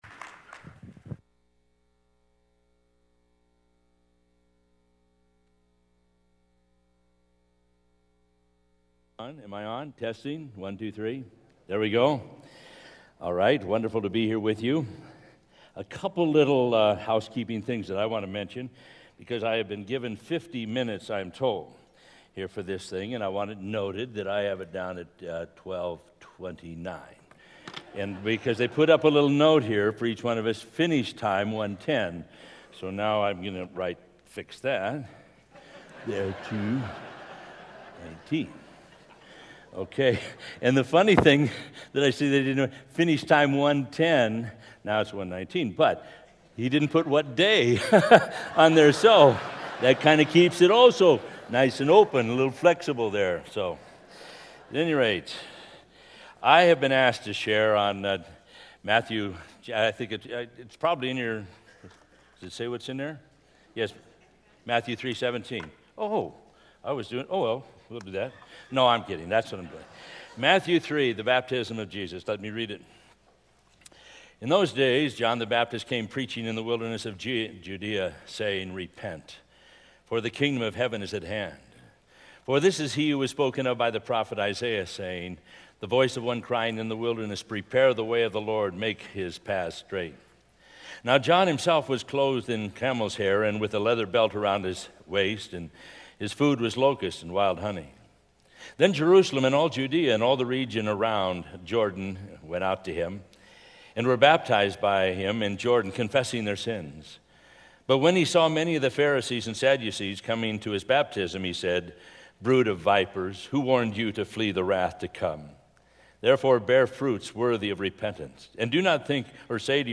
Southwest Pastors and Leaders Conference 2014